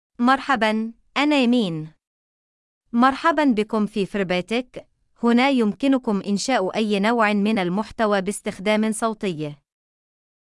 FemaleArabic (Algeria)
Amina is a female AI voice for Arabic (Algeria).
Voice sample
Listen to Amina's female Arabic voice.
Amina delivers clear pronunciation with authentic Algeria Arabic intonation, making your content sound professionally produced.